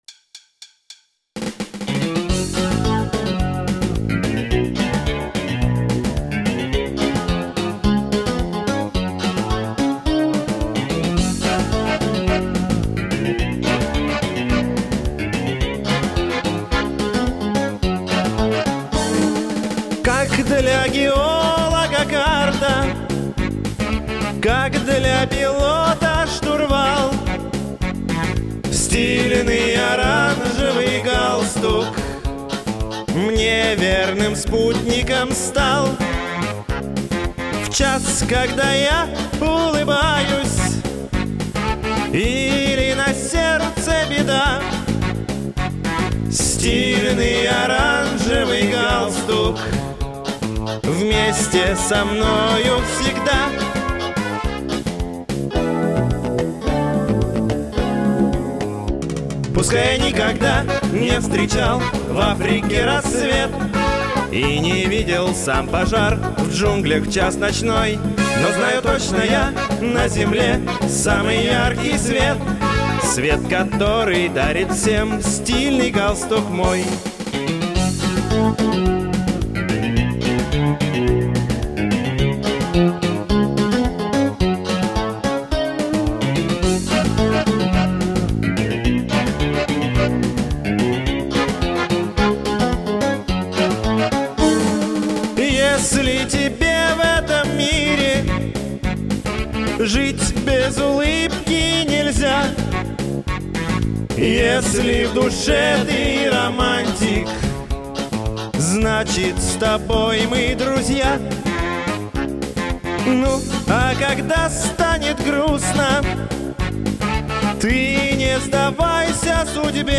Здесь я впервые на два голоса сам с собой попробовал петь.